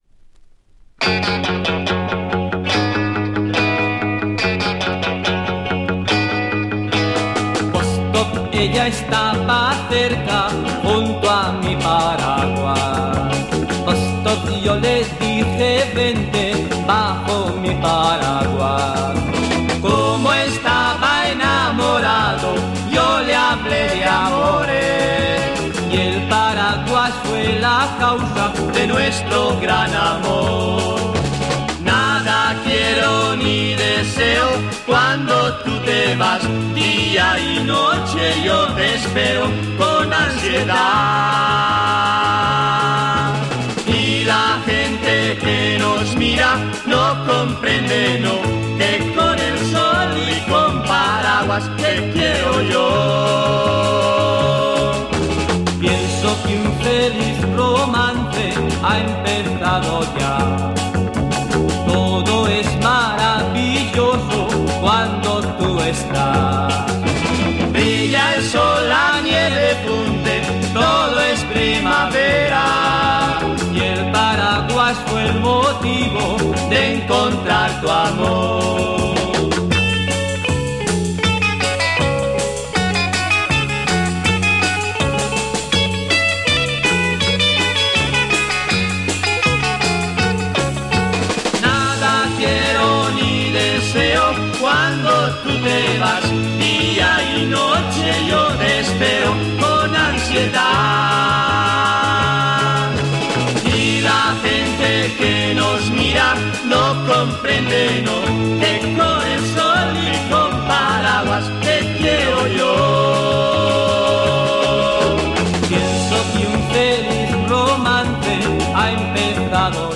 Rare Spanish Garage EP 66!